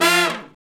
Index of /90_sSampleCDs/Roland LCDP06 Brass Sections/BRS_Section FX/BRS_R&R Falls